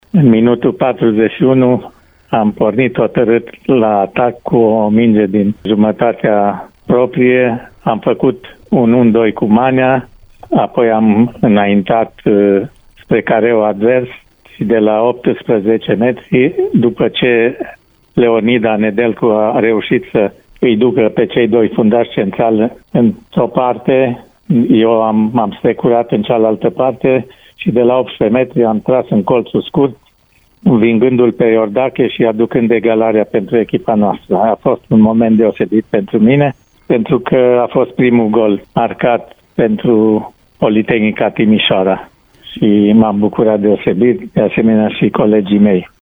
Fundaș stânga în acel meci, el a povestit într-un interviu pentru Radio Timișoara reușița de 1-1, de la finalul primei reprize: